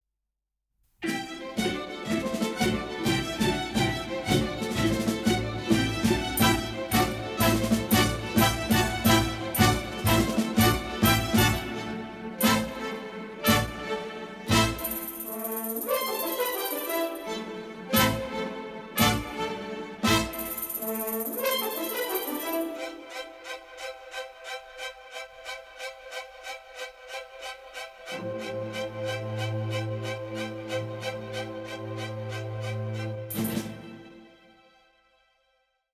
memorable, energetic and varied score